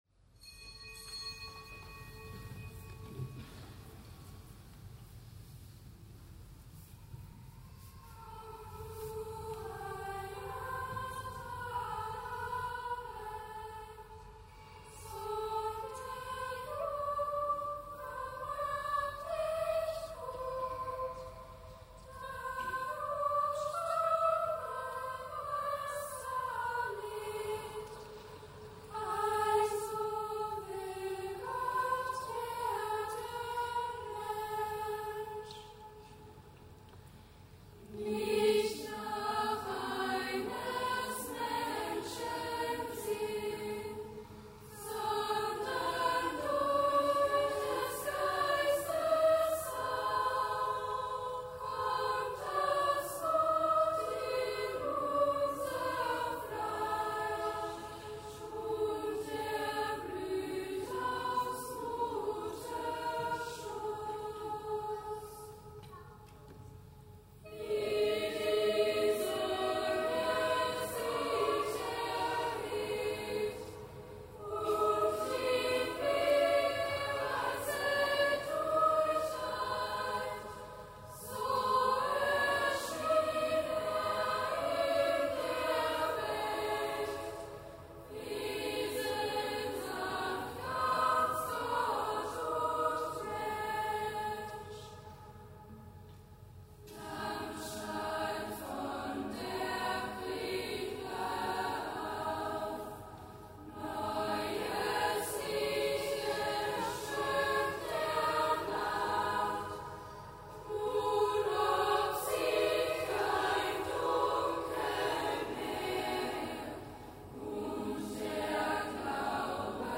Kapitelsamt am vierten Adventssonntag